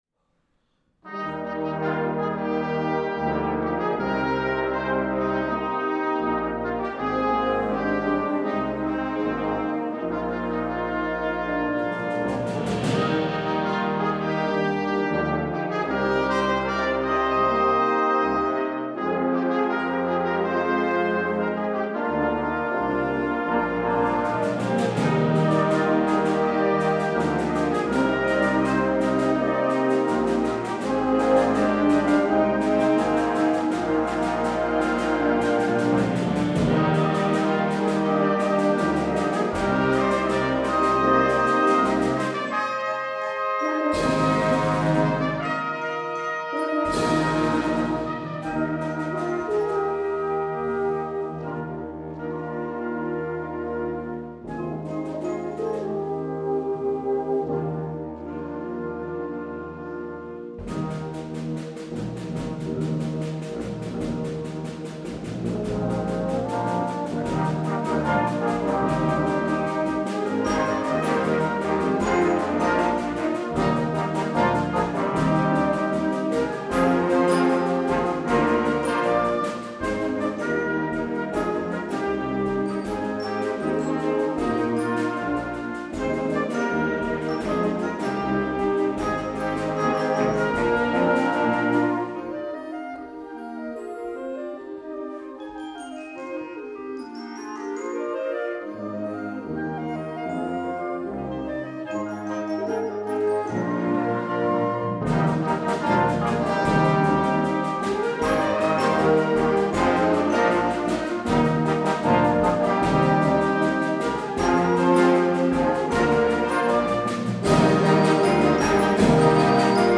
LANDESKONZERTMUSIK - JUGENDBLASORCHESTER
Grafenegg
>live